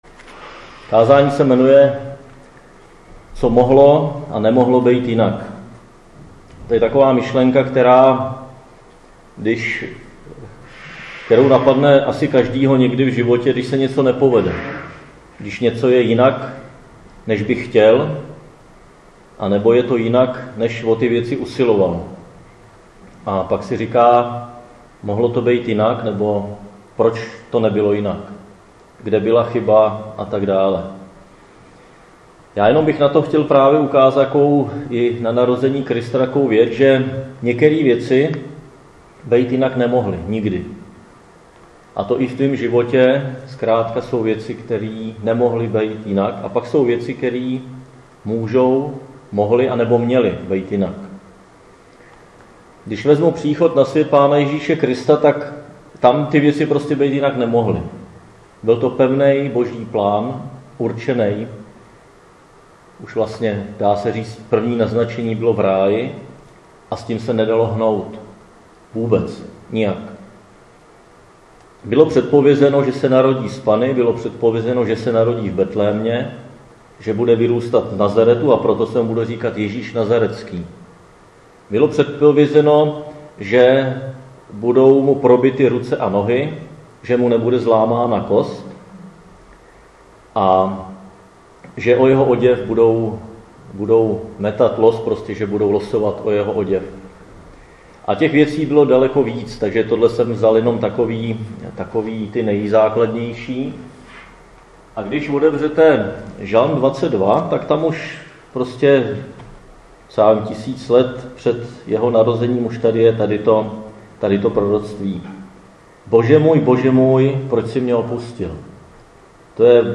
Křesťanské společenství Jičín - Kázání 24.12.2017